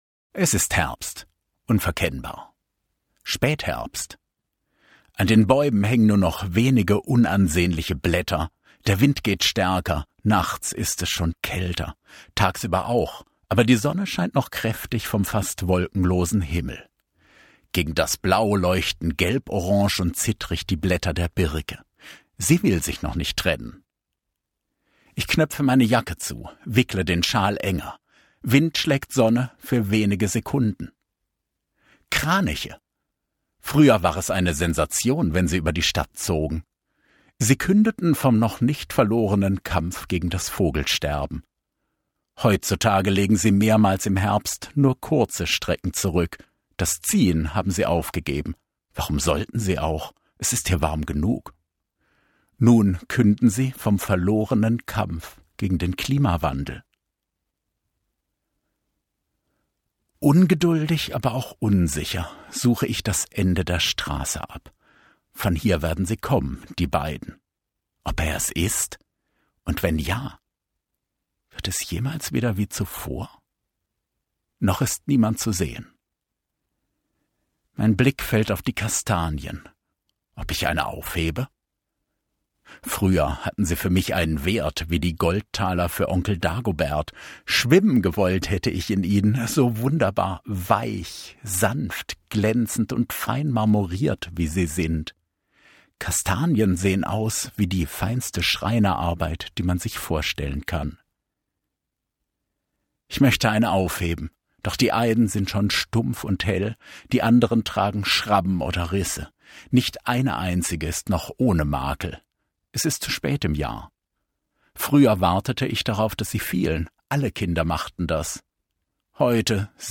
Male
Approachable, Authoritative, Character, Confident, Conversational, Cool, Corporate, Engaging, Friendly, Natural, Reassuring, Smooth, Versatile, Warm
Standard German, English with German/European accent
Microphone: Blue Bluebird SL